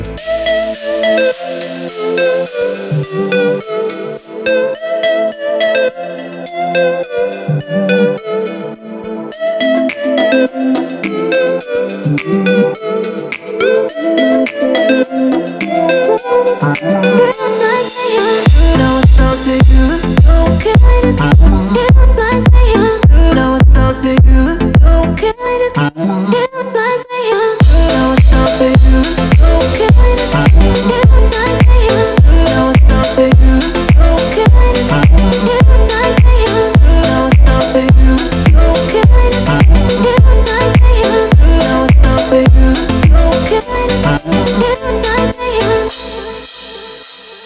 • Качество: 320, Stereo
ритмичные
мужской вокал
женский вокал
deep house
dance
Electronic
tropical house
теплые
электронный голос